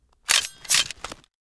melee0.wav